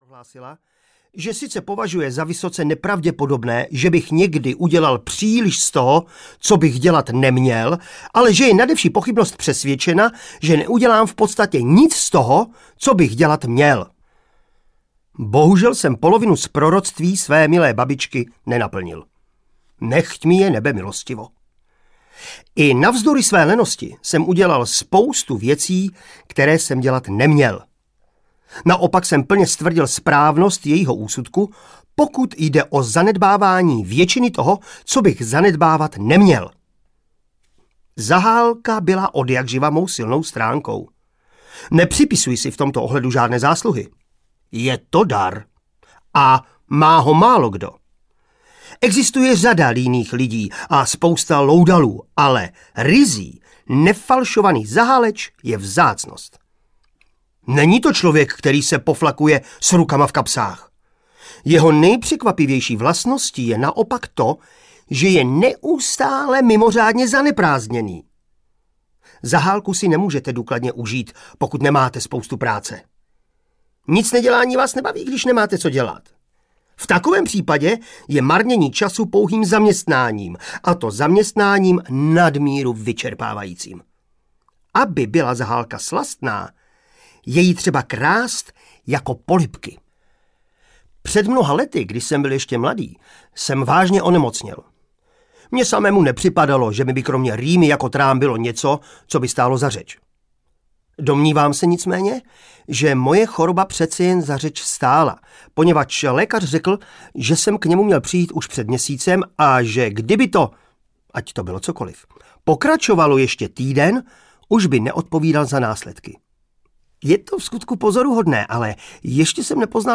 Audio knihaJalové myšlenky lenivého člověka
Ukázka z knihy
• InterpretMartin Dejdar